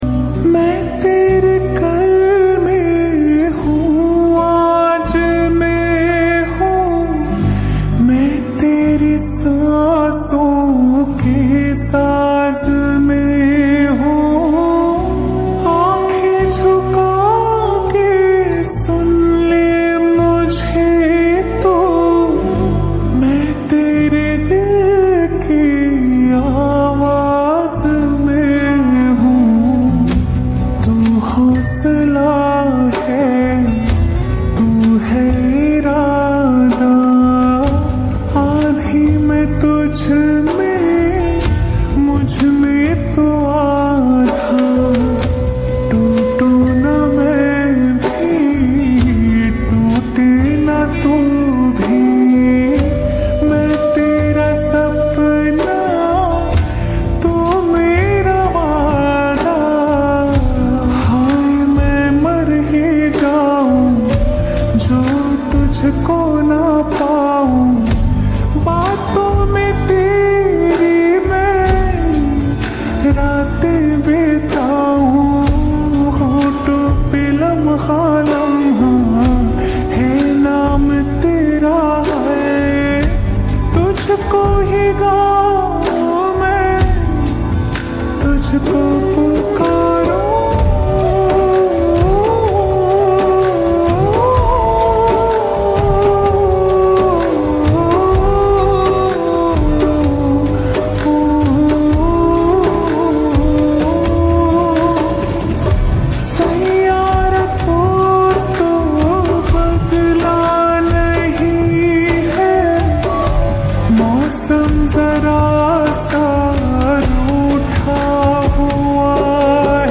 SLOWED REVERB 🎶 SAD LOFI SONG